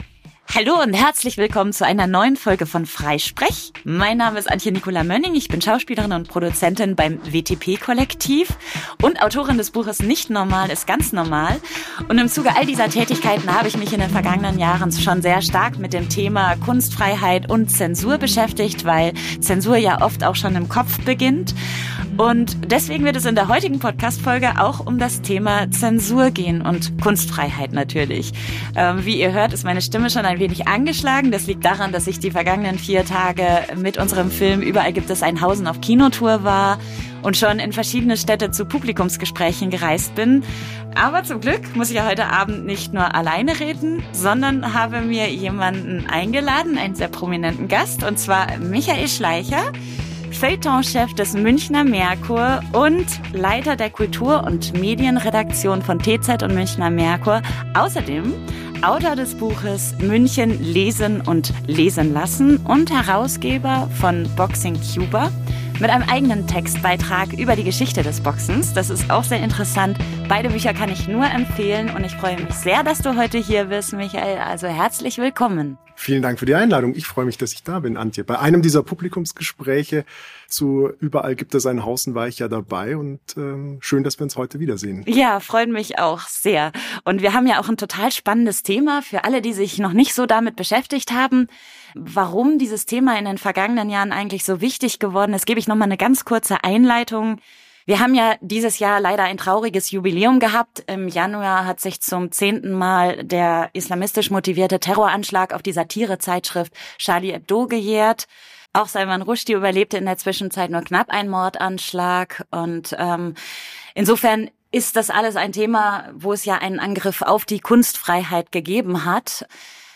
Zeit also, sich einmal anzuschauen, wie es eigentlich um die Kunstfreiheit in Deutschland bestellt ist. Im Gespräch